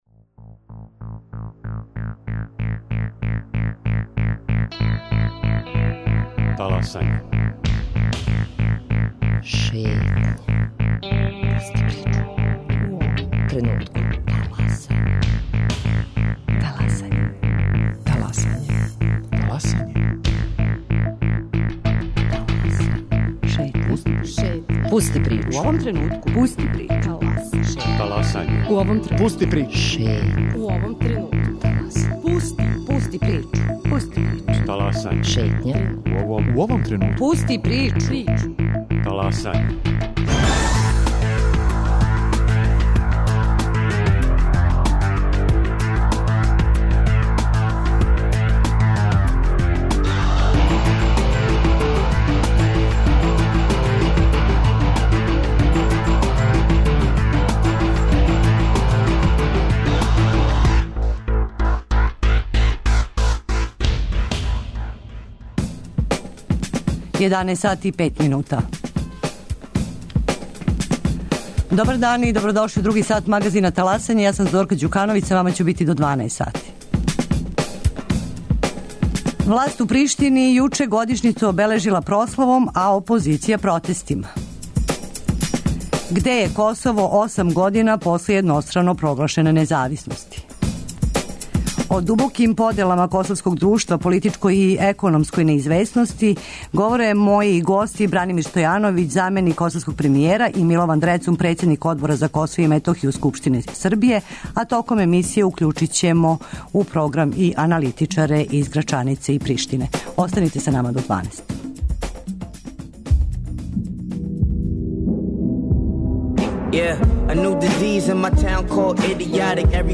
О дубоким поделама косовског друштва, политичкој и економској неизвесности говоре гости Бранимир Стојановић, заменик косовског премијера и Милован Дрецун, председник Одбора за Косово и Метохију Скупштине Србије, као и аналитичари из Грачанице и Приштине.